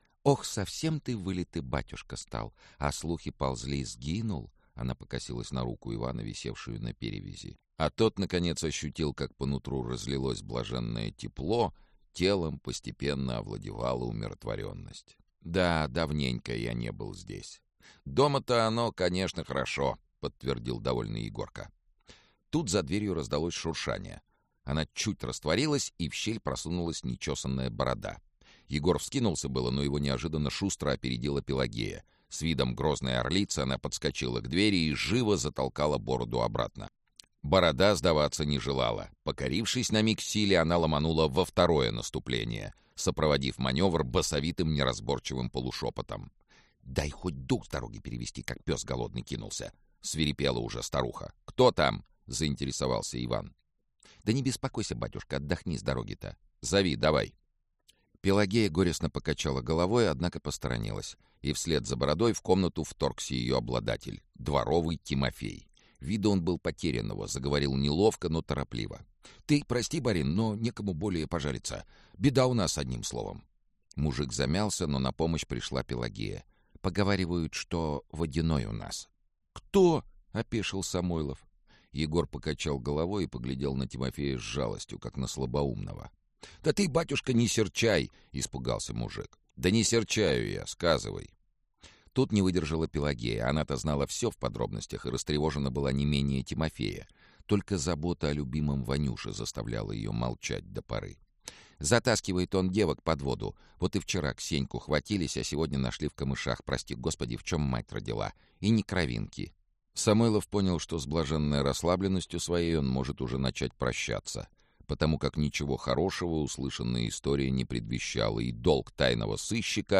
Аудиокнига Записки экспедитора Тайной канцелярии. Отпуск (фрагмент) | Библиотека аудиокниг
Отпуск (фрагмент) Автор Олег Рясков Читает аудиокнигу Сергей Чонишвили.